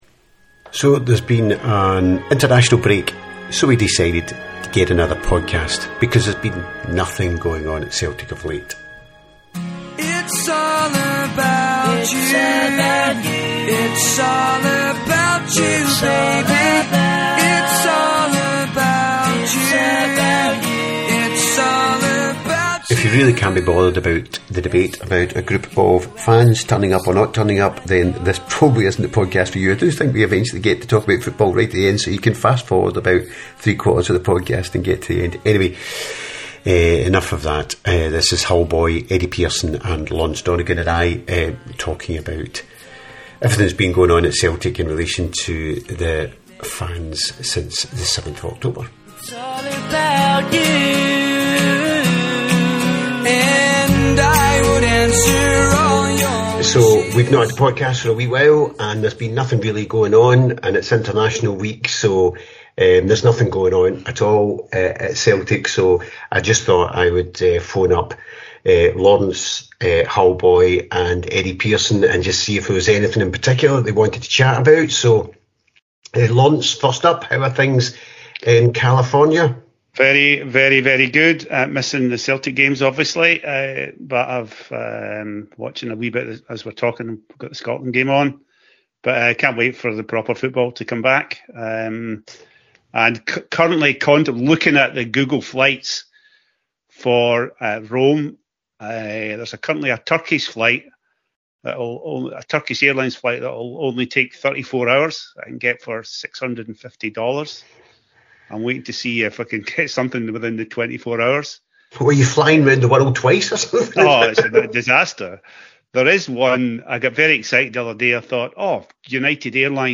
In general (and this is not a judgement by us, mere an assessment of the dynamics) we all agree that it’s probably the end of the Green Brigade as a recognised group within Celtic Park. We discuss the damage the banner of 7th October did or didn’t do to Celtic’s reputation and yes, we do get on to talk about football, specifically the Motherwell game. We very rarely edit the podcast and tend to put out the audio “as live” (as the recent ‘mother-in-law comments showed) however at times the debate got a little heated and there is a small section where we all talk over each other and it’s pretty much unlistenable and so if there sounds like a jump in the recording, it’s where this bit was taken out.